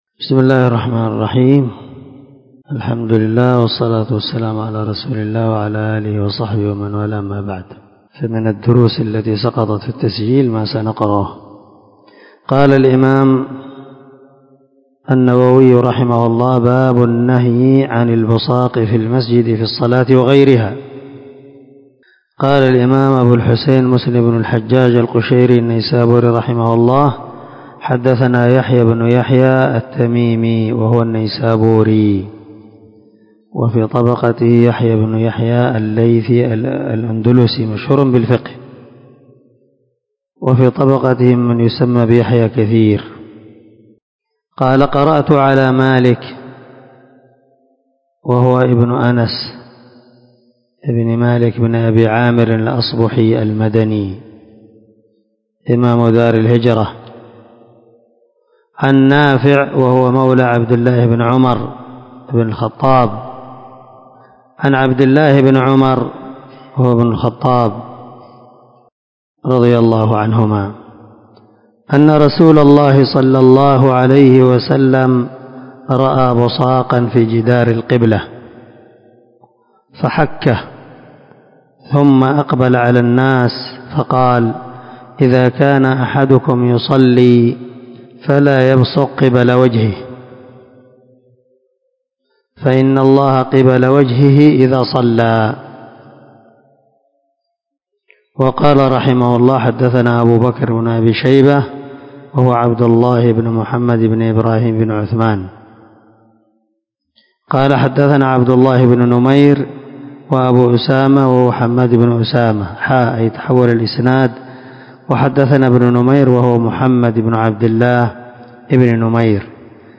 347الدرس 19 من شرح كتاب المساجد ومواضع الصلاة حديث رقم ( 547 - 550 ) من صحيح مسلم